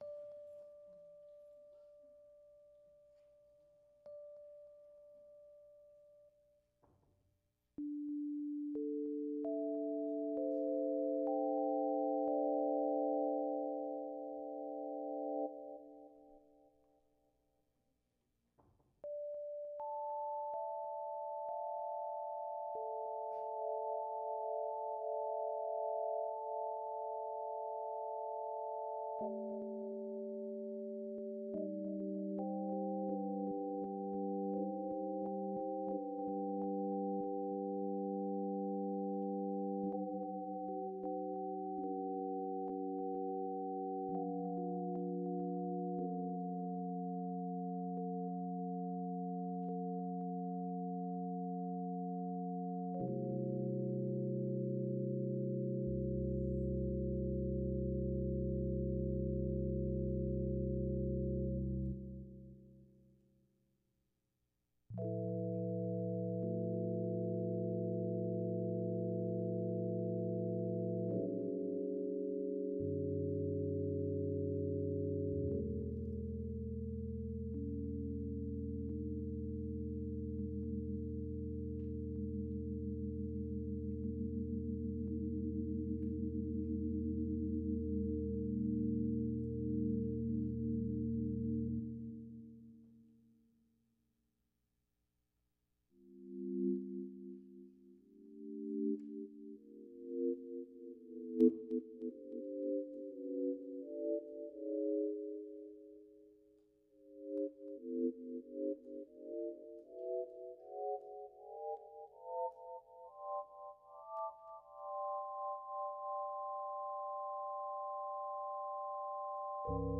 "Pseudo"-72-tet Improvisation
The levels in this fileare too low -- consider normalizing.
I recently performed what I call a "pseudo"-72-tet improvisation at mysenior recital. It's not quite 72-tet because I simply have twokeyboards hooked up via midi and tuned to the same patch and detunedone of them. Sometimes I'd tune one a quarter tone flat and play in24-tet, other times I'd tune a third of a semitone flat to get closerto a pure 7:4, and sometimes I'd tune a sixth of a semitone flat toget closer to a pure 5:4.